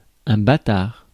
Ääntäminen
France (Paris): IPA: [ɛ̃ ba.taʁ]